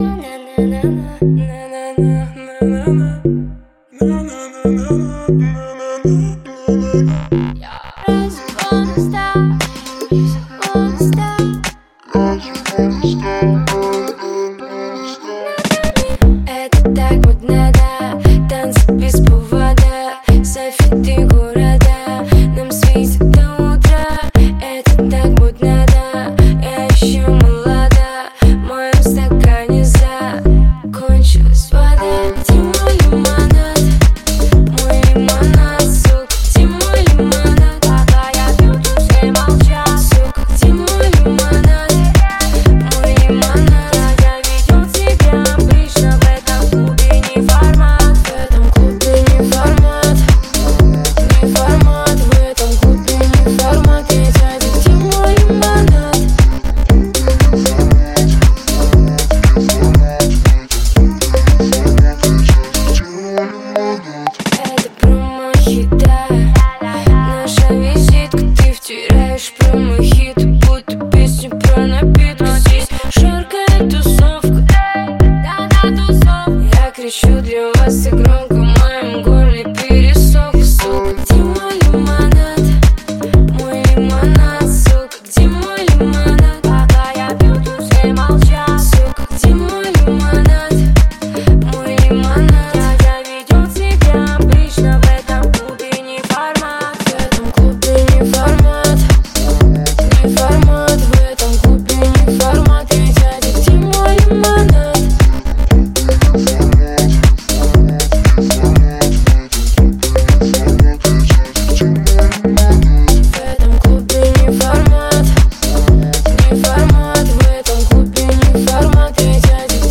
Поп-музыка
Жанр: Поп-музыка / В машину